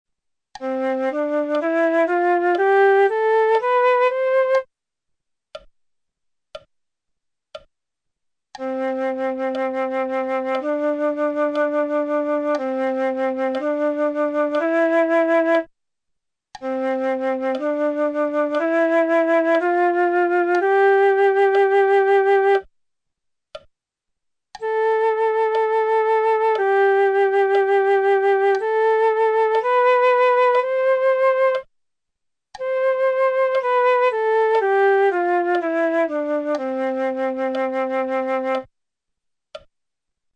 Prima dei dettati veri e propri, sentirai le note che verranno proposte, seguite da una battuta vuota scandita nella divisione dal metronomo.
Note: Do - Re - Mi - Fa - Sol - La - Si - Do
Tempo: 4/4